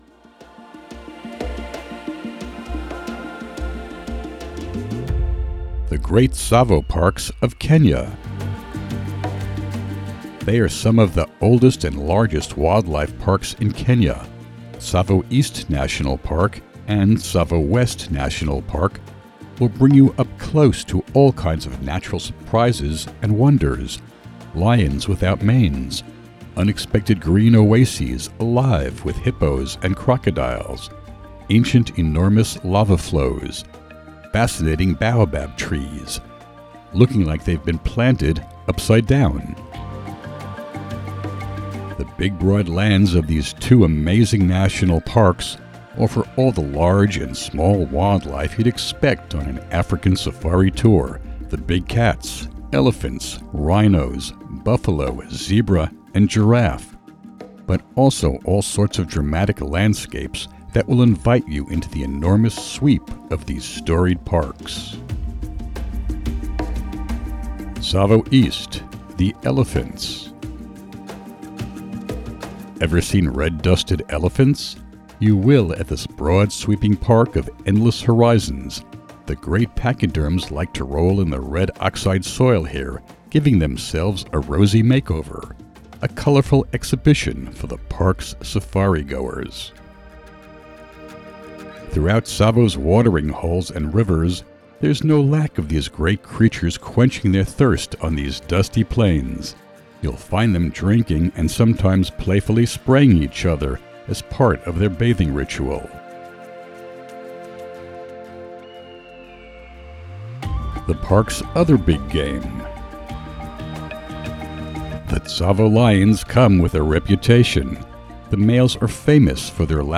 Listen to an audio version of this blog post!